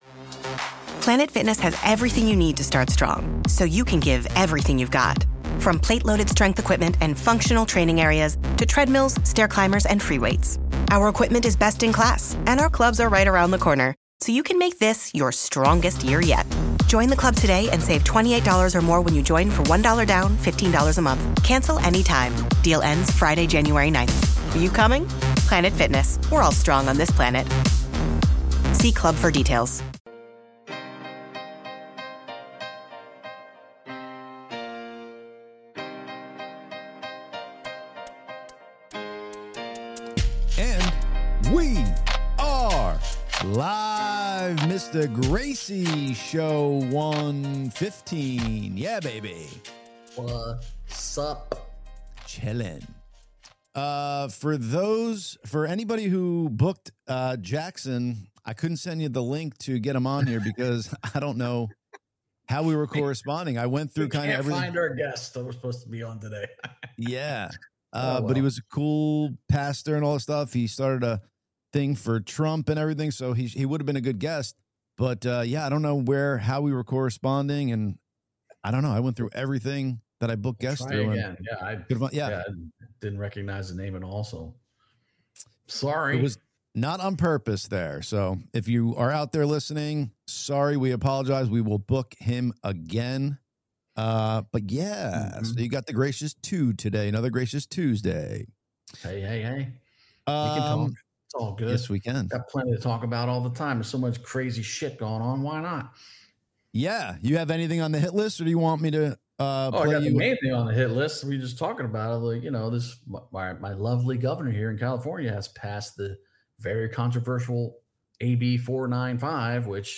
The Gracious Two LIVE Podcast - Every Tuesday at 1 pm EASTERN...